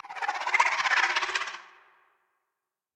Sfx_creature_arcticray_idle_04.ogg